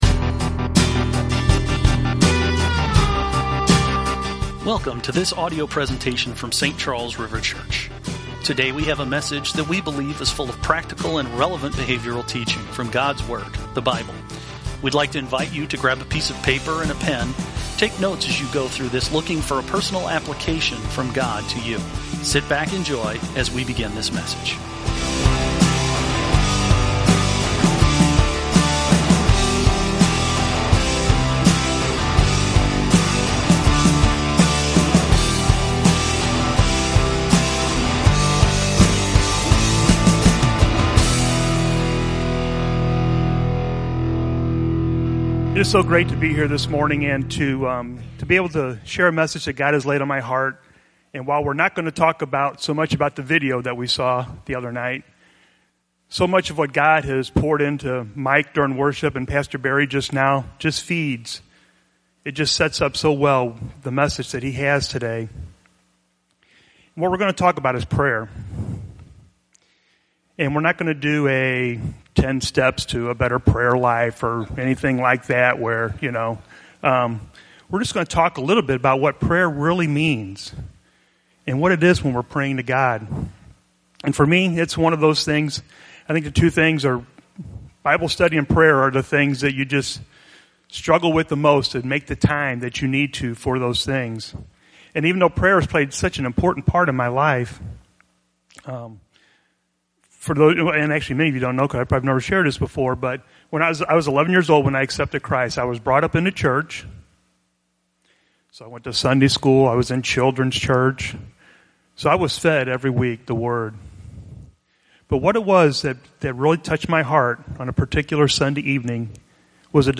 Weekly podcast recorded Sunday mornings during the Celebration Service at Saint Charles River Church in O'Fallon, Missouri.